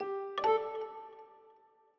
Longhorn Ten Beta - Notify Messaging.wav